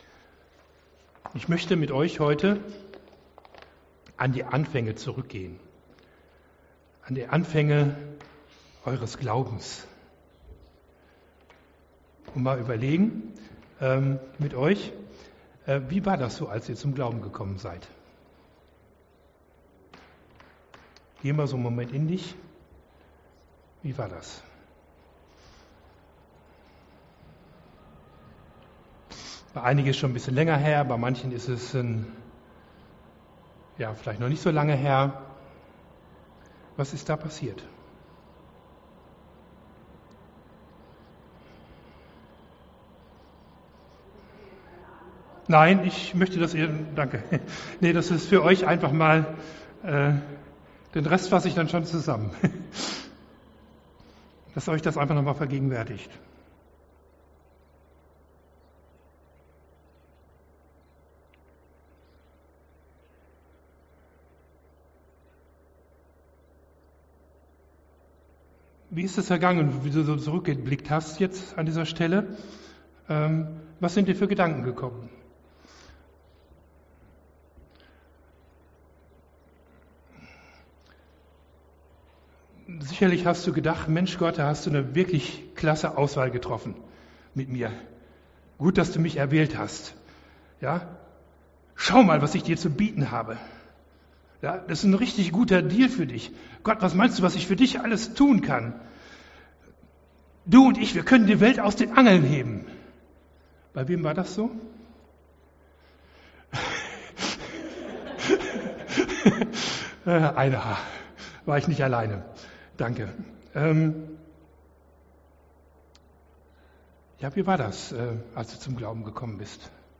Bibeltext zur Predigt: Römer 15,17 Ich habe also in Christus Jesus etwas zum Rühmen in den Dingen vor Gott.